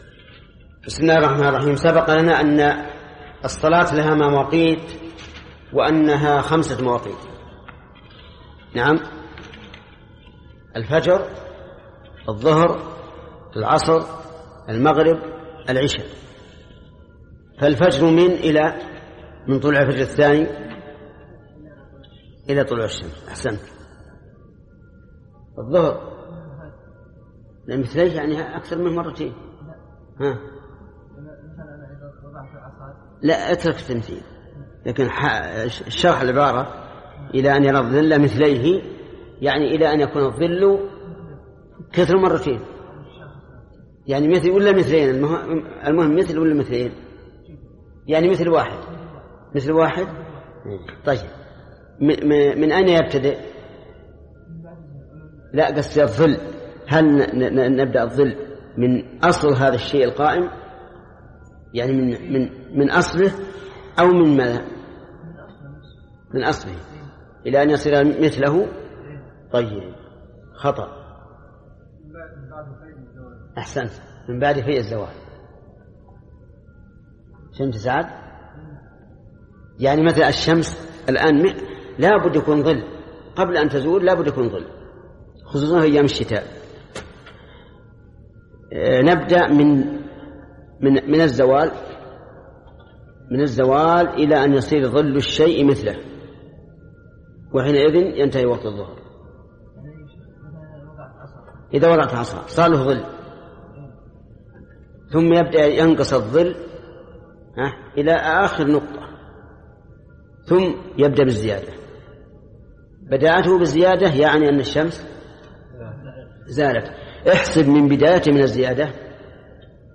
📬 الدرس الثاني من شرح كتاب عمدة الأحكام لفقيه الزمان العلامة محمد بن عثيمين رحمه الله